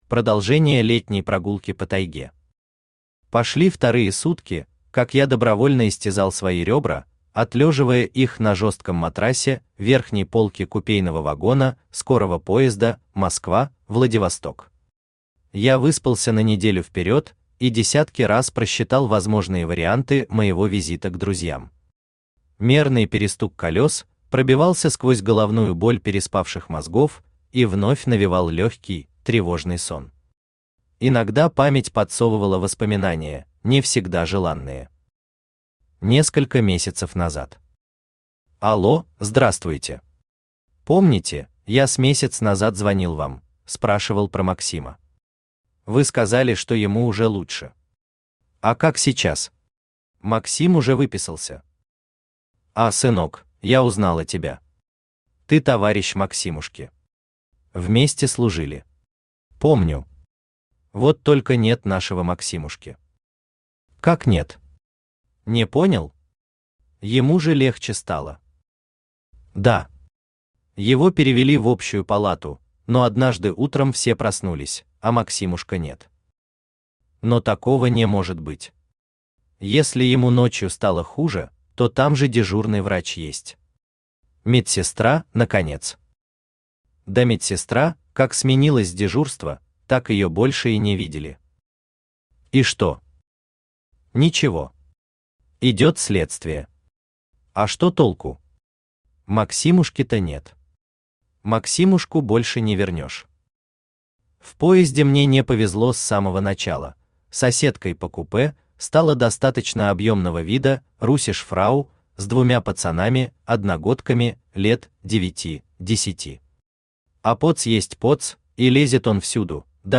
Aудиокнига Возвращение в тайгу Автор Владимир Мищенко Читает аудиокнигу Авточтец ЛитРес.